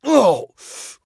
pain_7.ogg